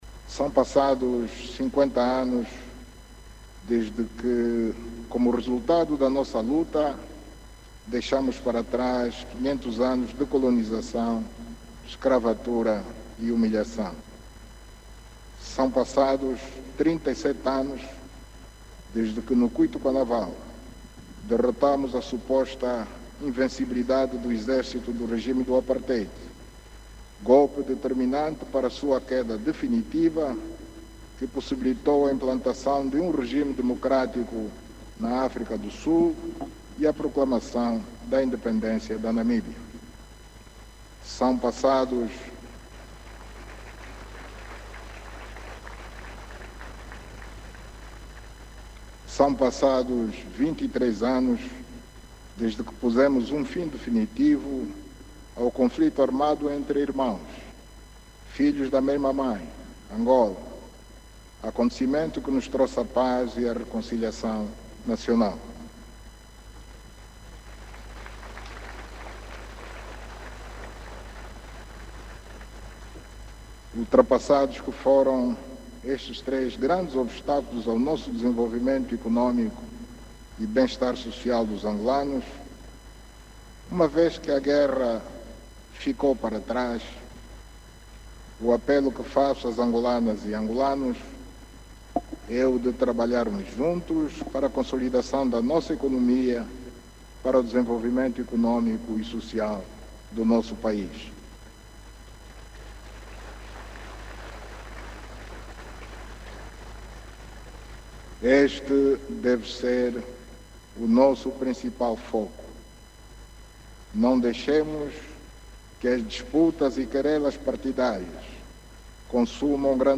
O Chefe de Estado fez estes pronunciamentos durante o seu discurso à Nação, na Praça da República, em Luanda, onde decorre o acto central das comemorações dos 50 anos da Independência Nacional.